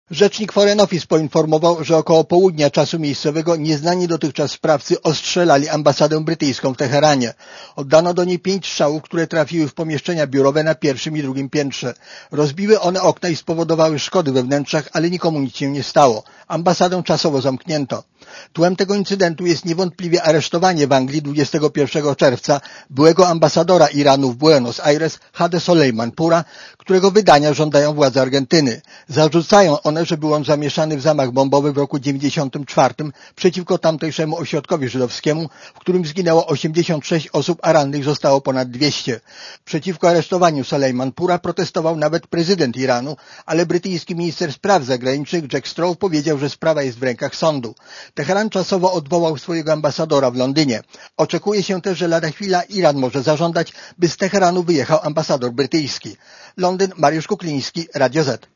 Posłuchaj relacji korespondenta Radia Zet (228 KB)